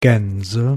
Ääntäminen
Ääntäminen Tuntematon aksentti: IPA: /ɡɛnzə/ Haettu sana löytyi näillä lähdekielillä: saksa Käännöksiä ei löytynyt valitulle kohdekielelle. Gänse on sanan Gans monikko.